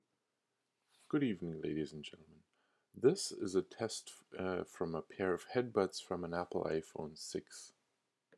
Apple EarPodswired earbuds, included with many apple products